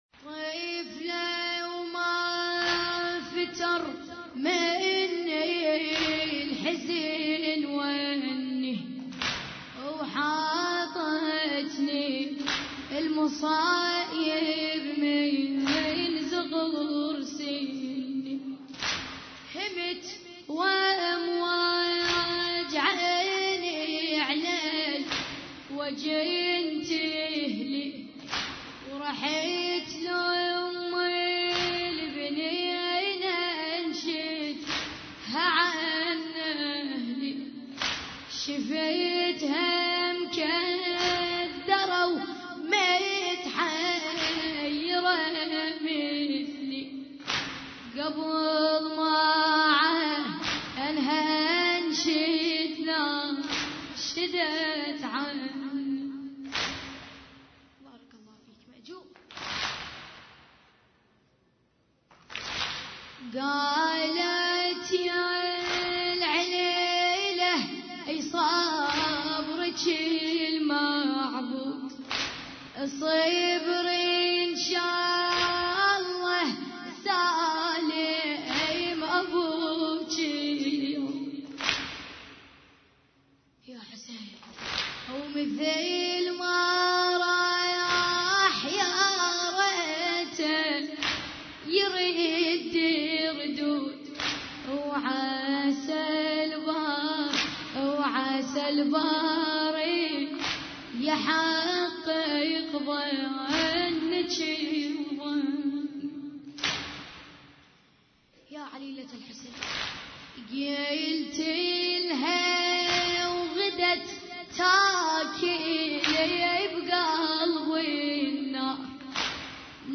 عزاء ليلة 3 محرم 1433 هجري